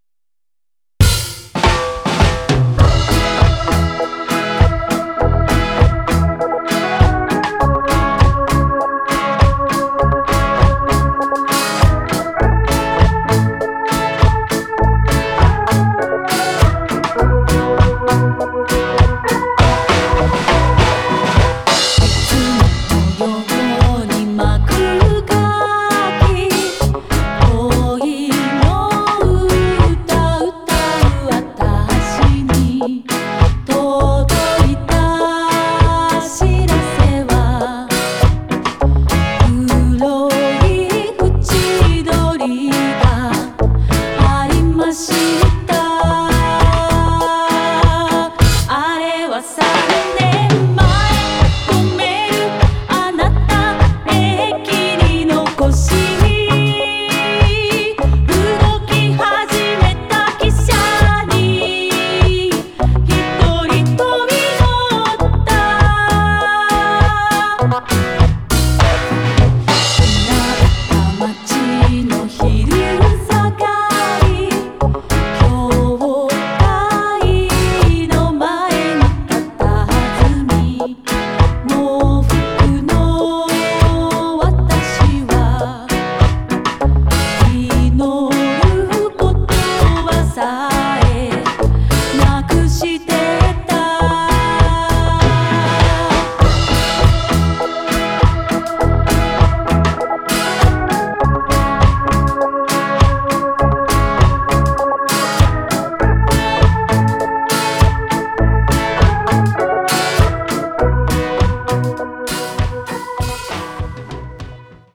【7"INCH】(再プレス)
ジャンル(スタイル) JAPANESE REGGAE